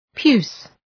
Προφορά
{pju:s}